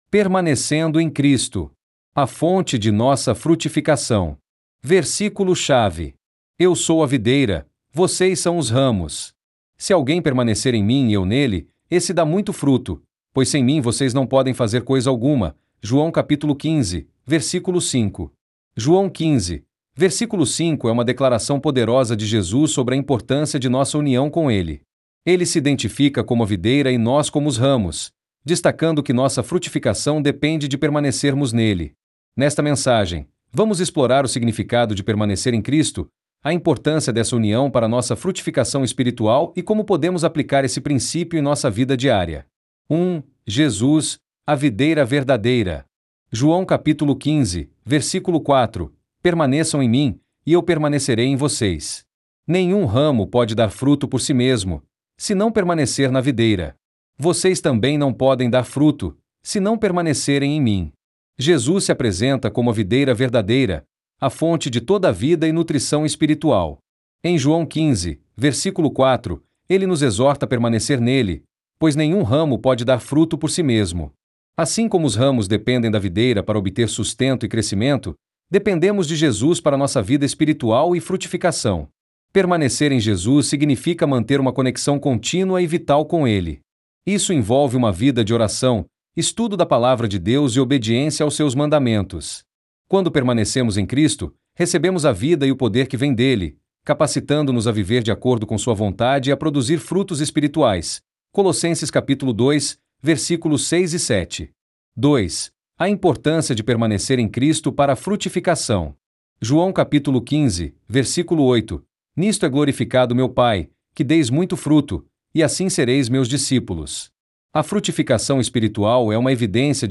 DEVOCIONAL